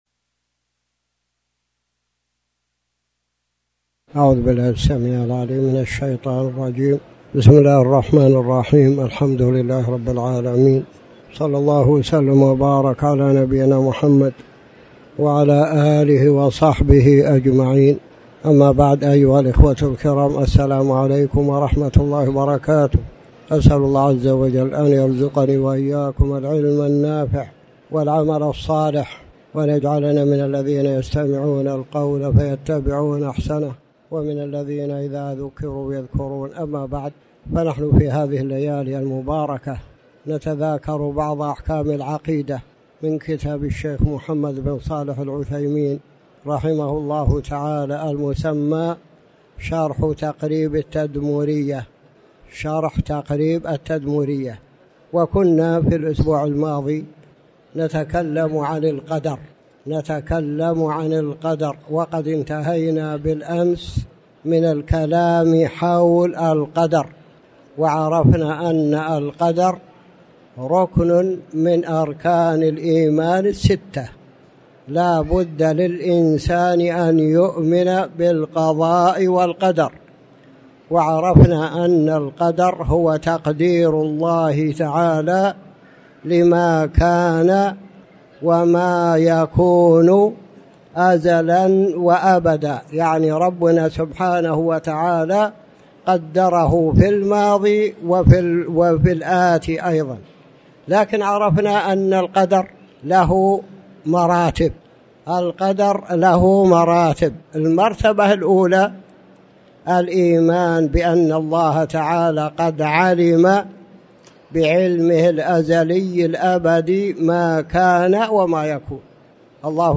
تاريخ النشر ١٩ ربيع الثاني ١٤٤٠ هـ المكان: المسجد الحرام الشيخ